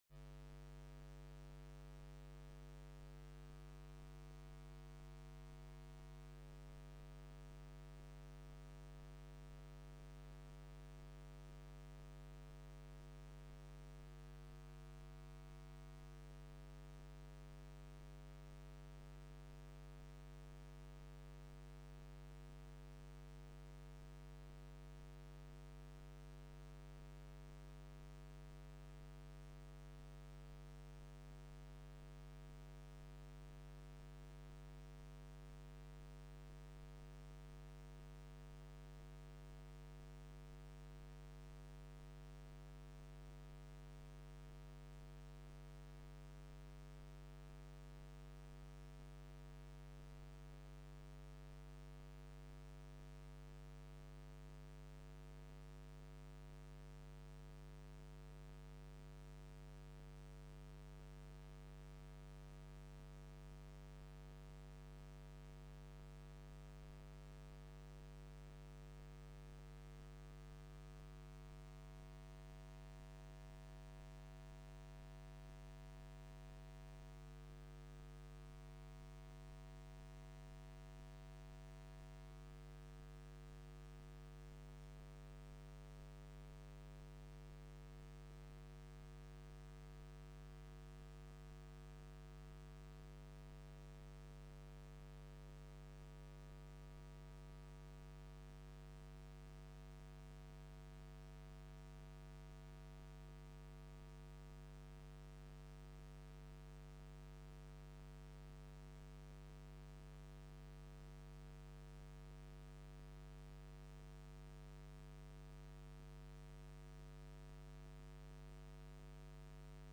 Gemeenteraad 17 juni 2013 19:30:00, Gemeente Albrandswaard
Bijzondere Raadsvergadering
Locatie: Raadzaal
4. Toespraken